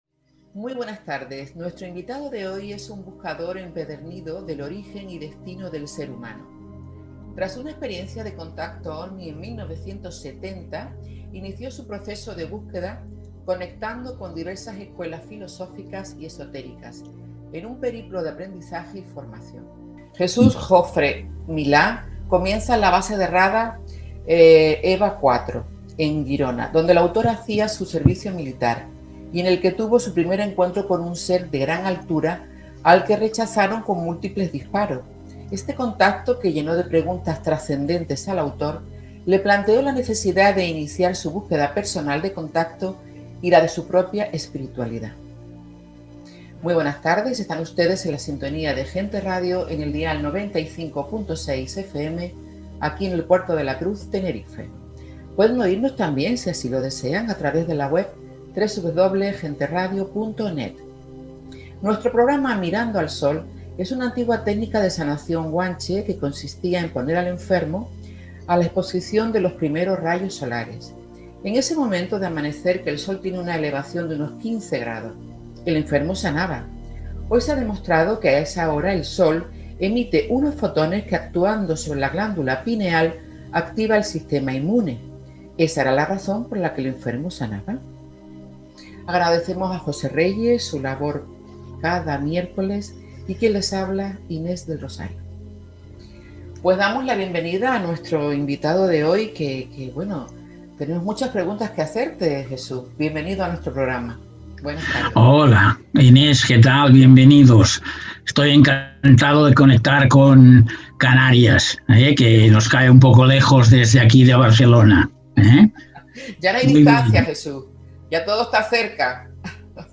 Grabado en Zoom con todo el amor del mundo